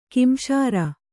♪ kimśara